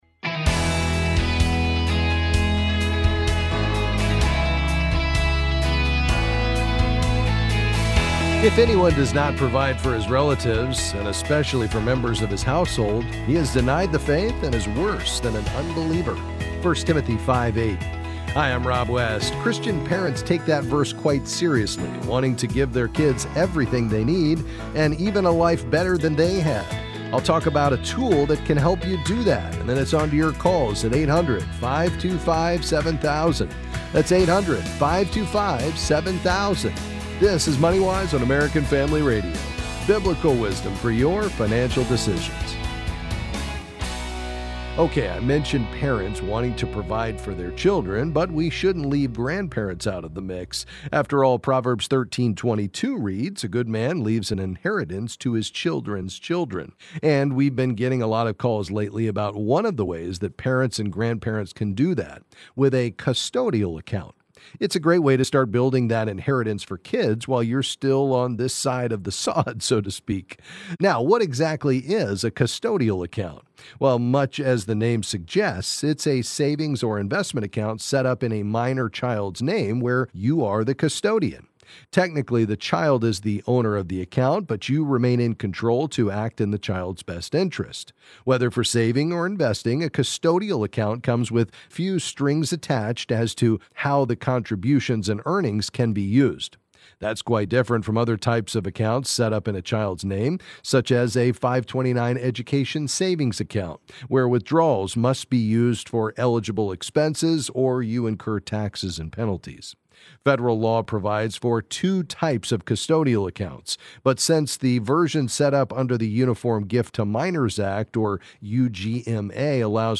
Then he’ll answer your calls and questions on a variety of financial topics.